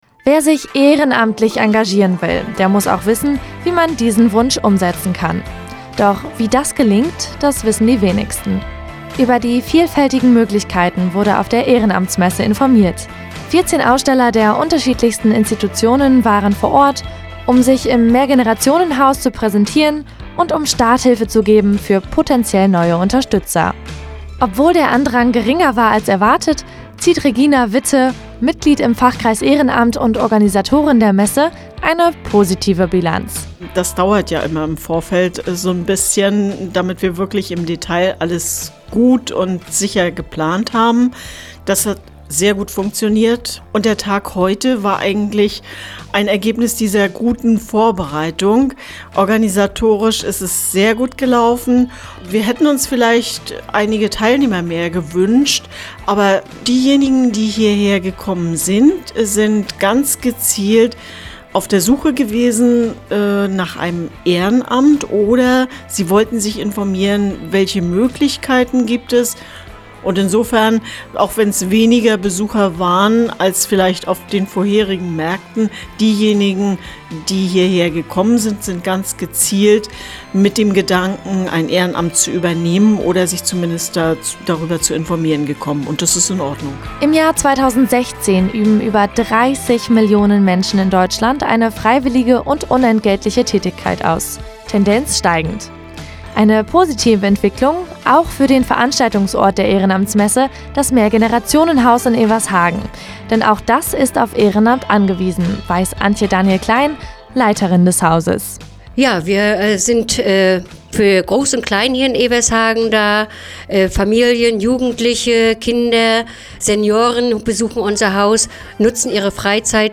Ehrenamtsmarkt in Evershagen